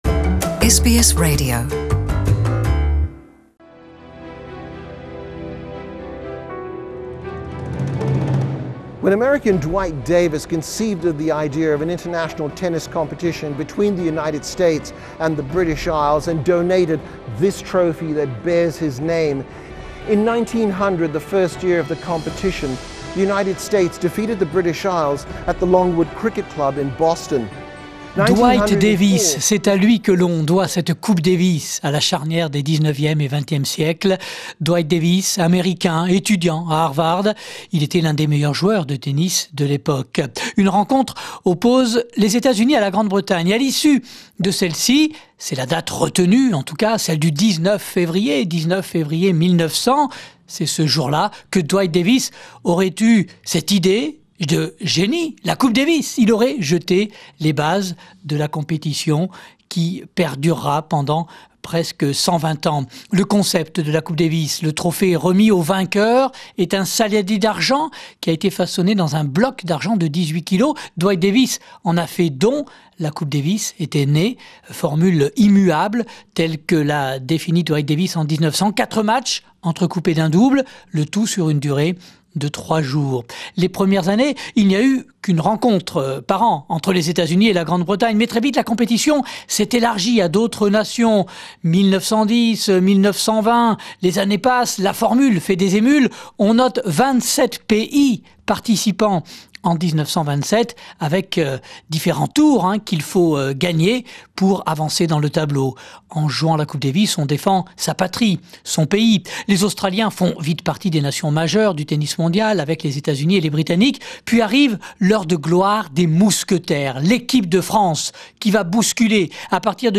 Le 9 février 1900 était lancée l’idée de la Coupe Davis de tennis, une compétition par équipe entre pays dont la formule a tenu jusqu’en 2018, avant la grande réforme en cours. Retour sur l’histoire de la Coupe avec les documents sonores de l’Institut National de l’Audiovisuel.